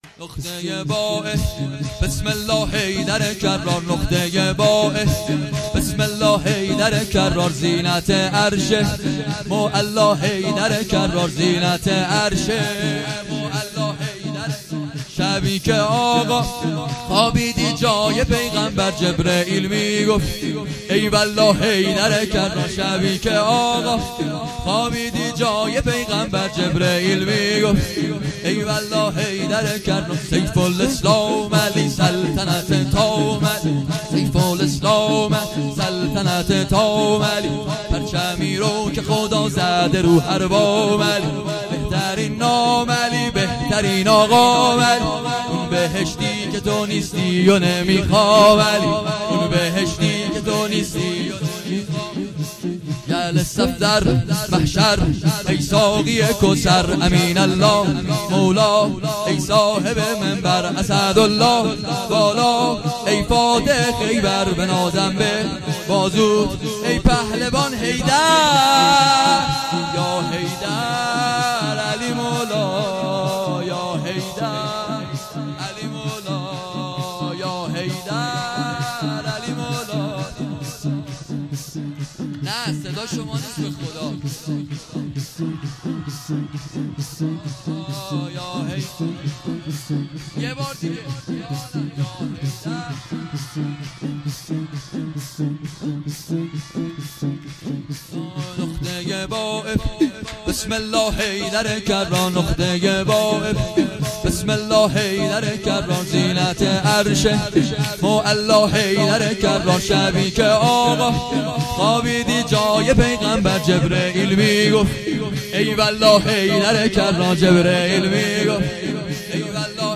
سرود جدید شور زیبا